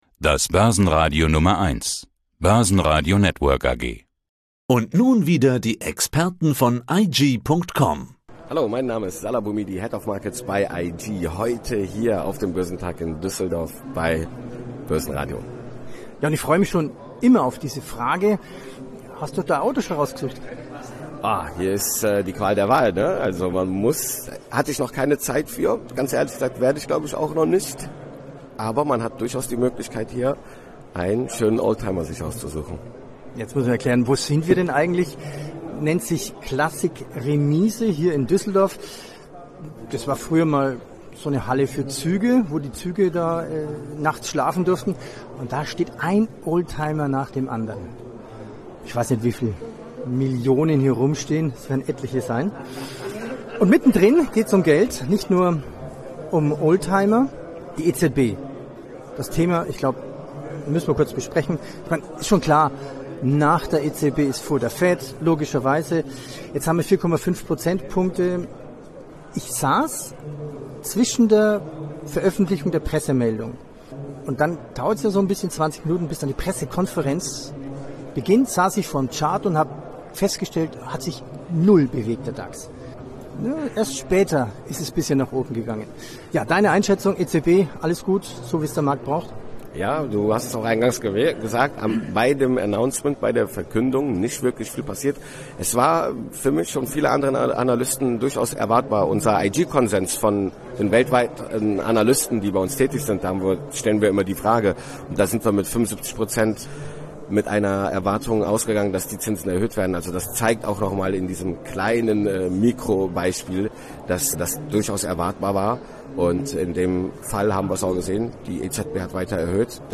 auf dem Börsentag Düsseldorf 2023